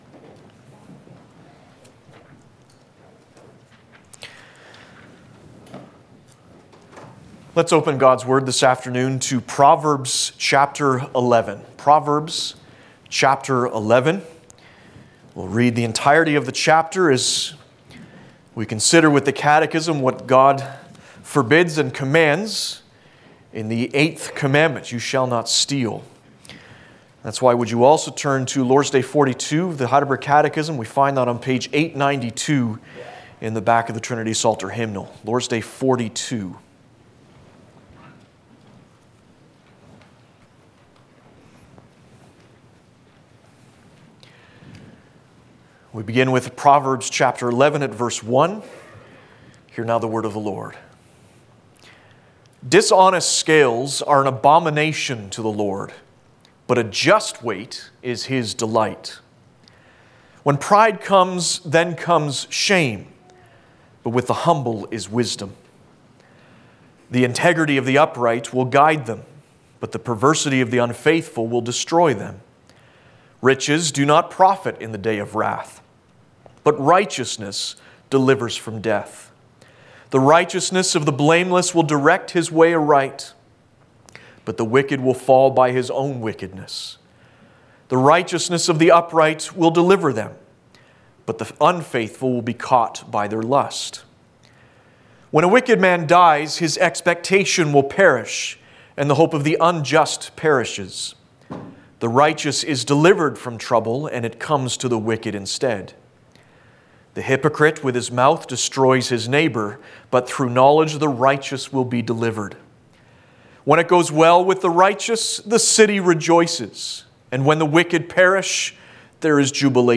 4.SermonA-just-Weight-1.mp3